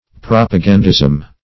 Search Result for " propagandism" : The Collaborative International Dictionary of English v.0.48: Propagandism \Prop`a*gan"dism\, n. [Cf. F. propagandisme.]